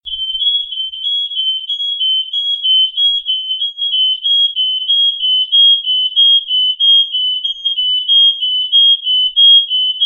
Aus diesem Grund haben wir die Warnmelder Alarme zahlreicher aktueller Modelle für Sie aufgezeichnet.
hekatron-genius-plus-x-funkrauchmelder-alarm.mp3